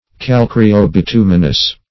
Search Result for " calcareo-bituminous" : The Collaborative International Dictionary of English v.0.48: Calcareo-bituminous \Cal*ca"re*o-bi*tu"mi*nous\, a. Consisting of, or containing, lime and bitumen.